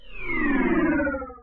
machine_power_off.ogg